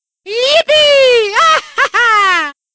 Mario - Yippee A-haha Bouton sonore